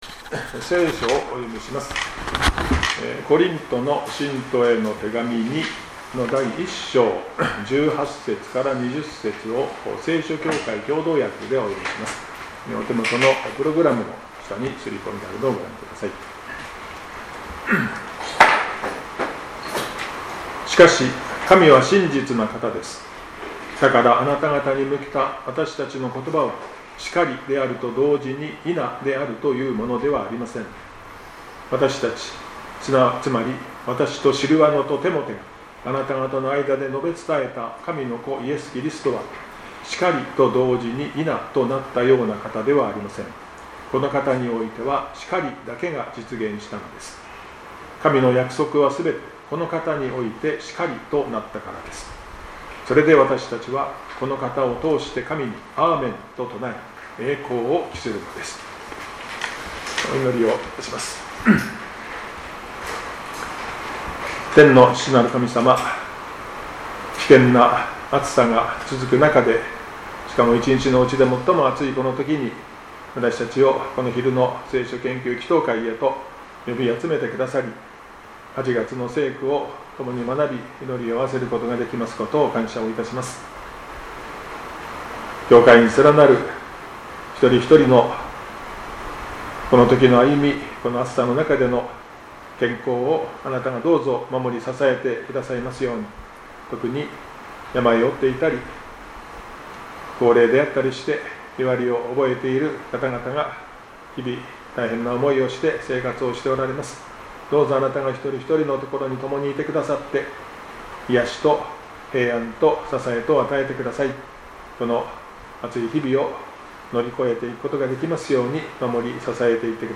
２０２５年８月の聖句についての奨励（８月６日 昼の聖書研究祈祷会）